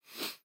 Звуки шмыганья носом
Шепчущий звук нежного нюхательного движения